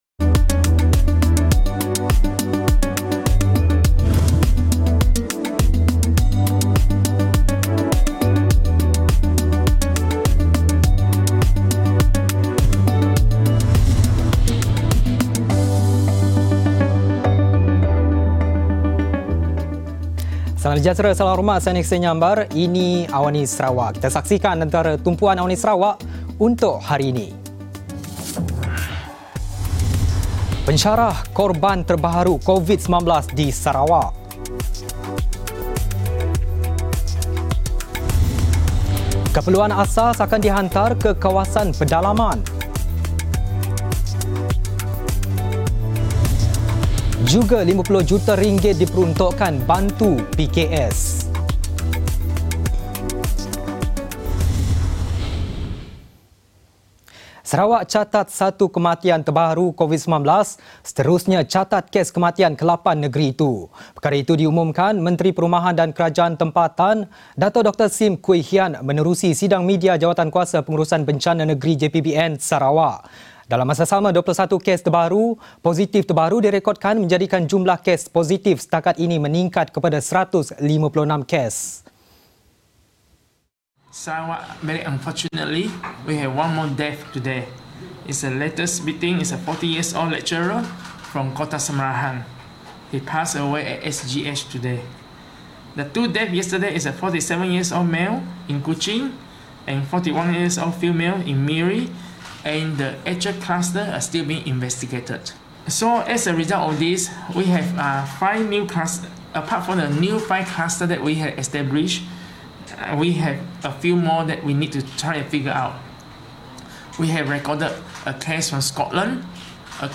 Laporan berita ringkas dan padat